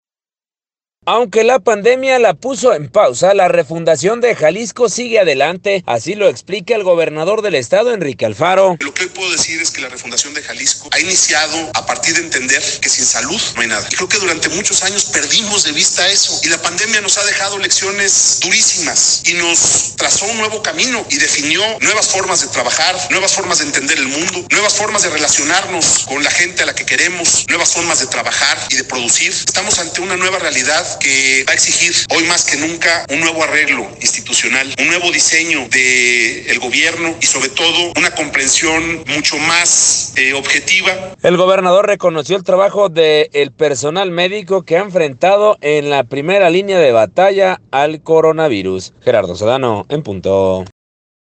Aunque la pandemia la puso en pausa, la refundación de Jalisco sigue adelante. Así lo explica el gobernador del estado, Enrique Alfaro: